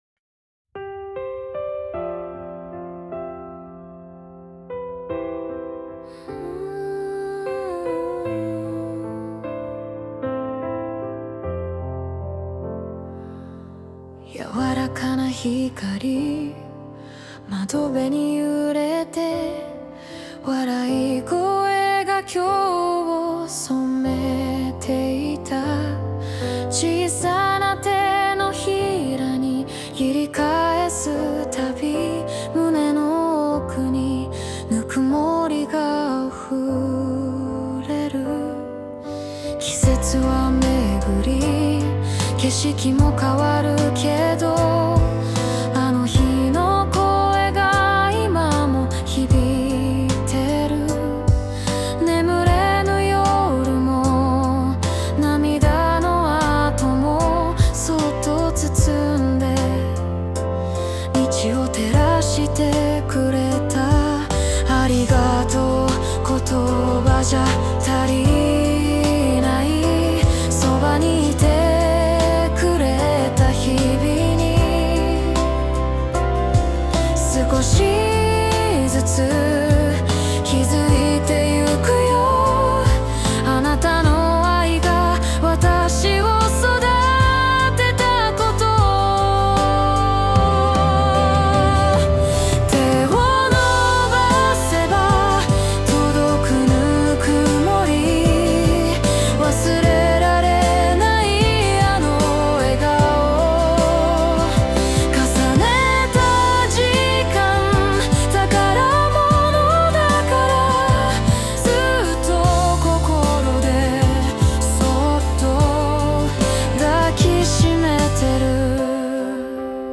著作権フリーオリジナルBGMです。
女性ボーカル（邦楽・日本語）曲です。
この歌は、そんな想いをそっと抱きしめるバラードです